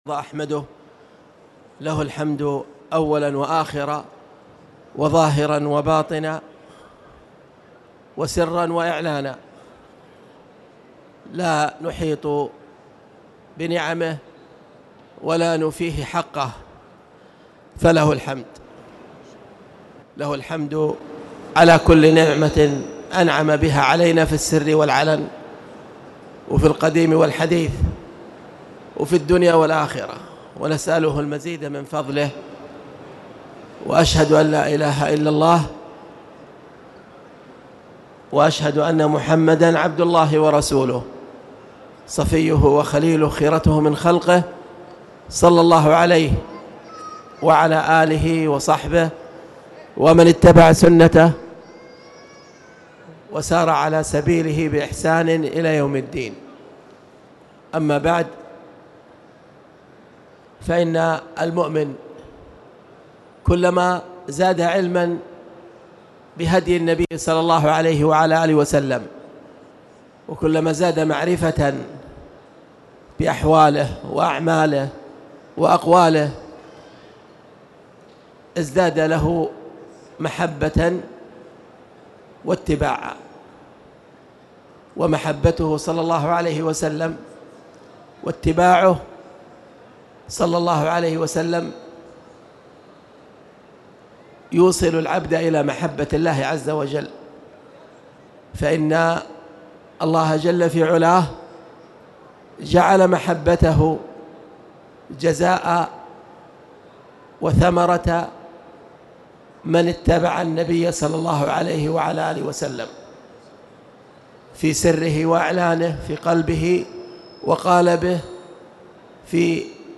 تاريخ النشر ١٦ رمضان ١٤٣٧ هـ المكان: المسجد الحرام الشيخ